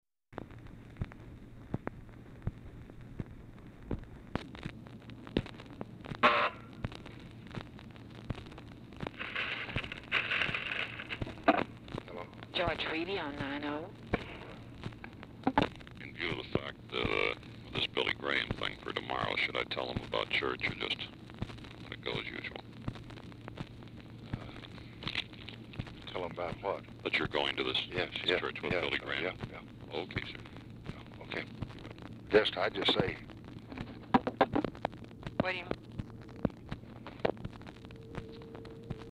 LBJ IS CUT OFF AT END OF CALL
Format Dictation belt
Location Of Speaker 1 Oval Office or unknown location
Specific Item Type Telephone conversation